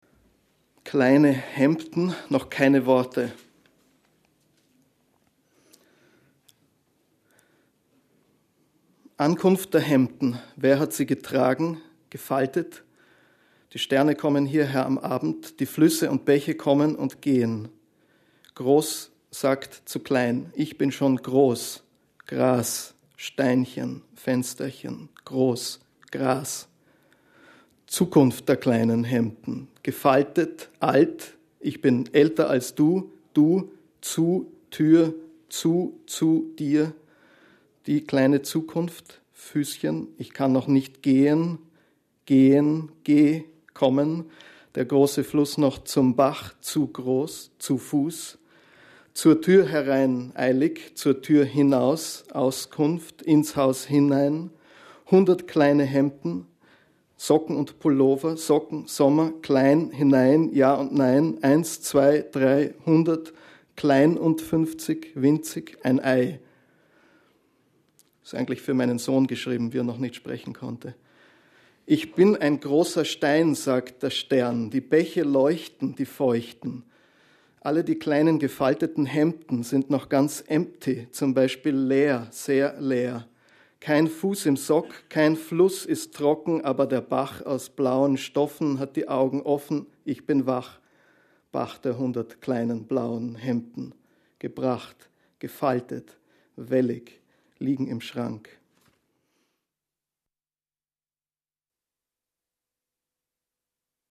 Lesung von Peter Waterhouse in der literaturWERKstatt Berlin zur Sommernacht der Lyrik – Gedichte von heute